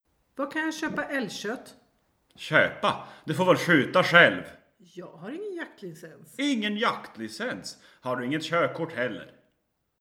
Soundfiles Dialog (schwedisch):